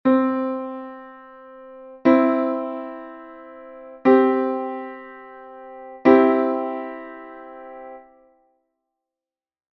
- Perfecto Maior: formado por unha 3ª Maior e unha 5ª Xusta.